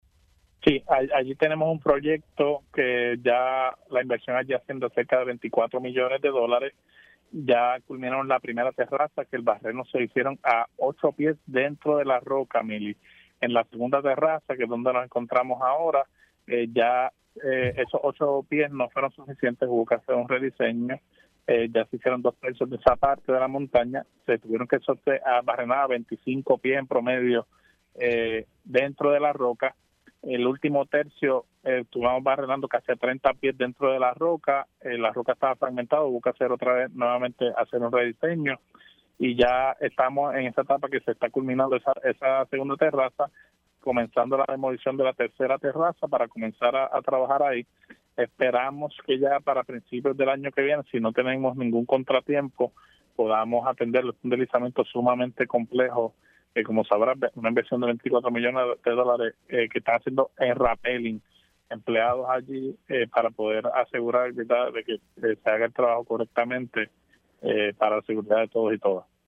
El secretario del Departamento de Transportación y Obras Públicas (DTOP), Edwin González confirmó en Pega’os en la Mañana que los trabajos en el Expreso Luis A. Ferré (PR-52) están encaminados para concluir a principios del 2026.